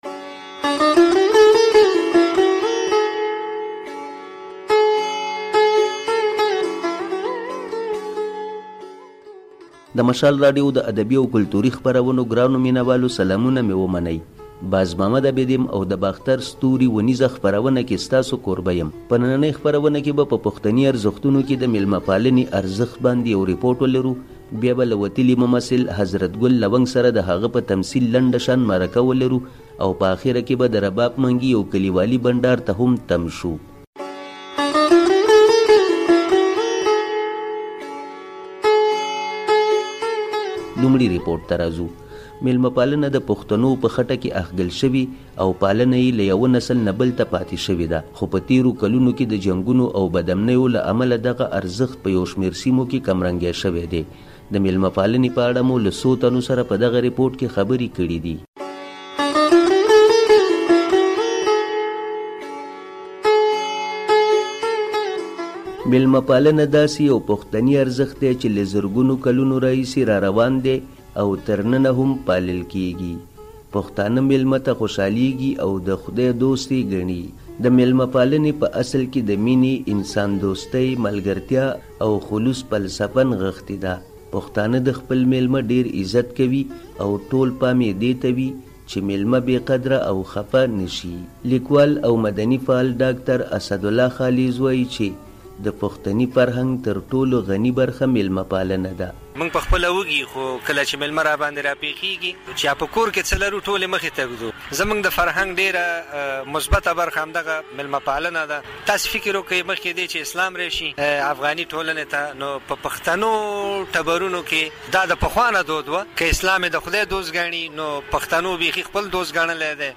د باختر ستوري په خپرونه کې په پښتني کلتور کې د ميلمه پالنې په ارزښت يو ريپوټ